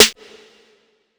TC2 Snare 32.wav